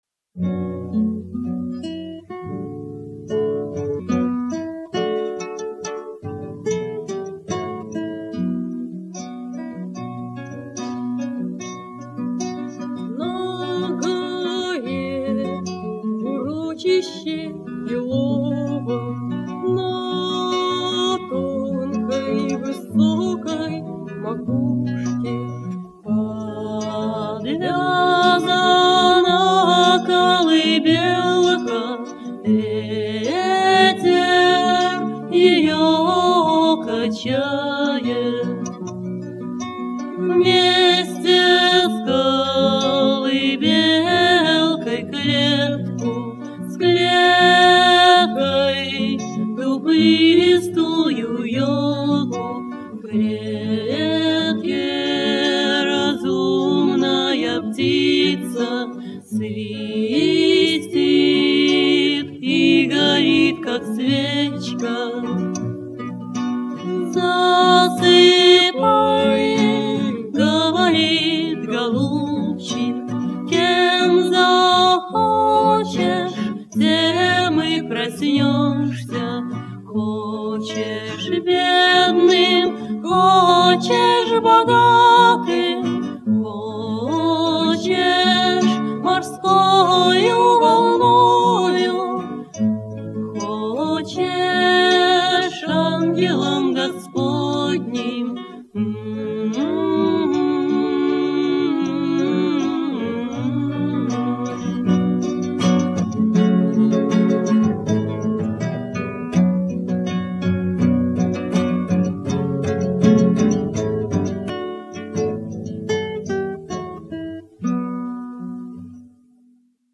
Песни на стихотворение:
2. «Дуэт Мистраль – Колыбельная» /